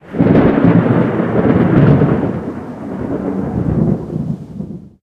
Звук продолжительный раскат грома.